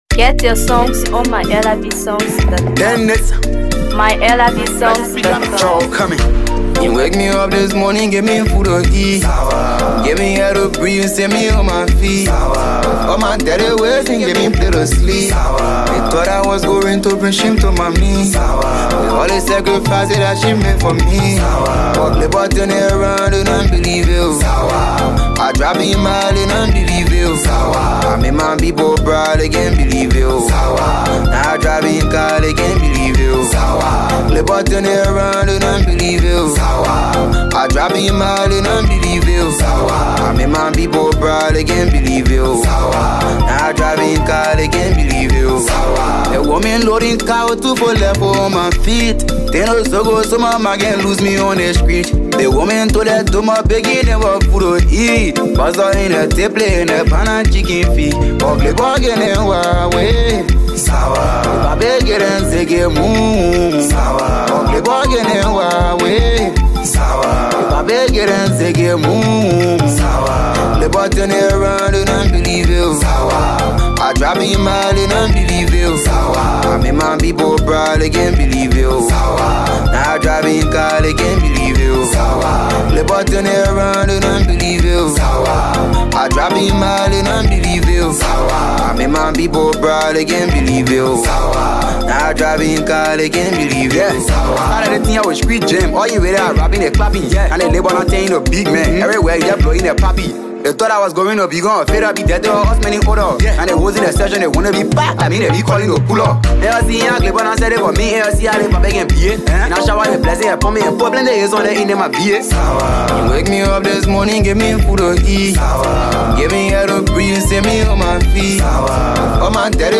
Afro PopMusic
resulting in a sound that is both nostalgic and fresh.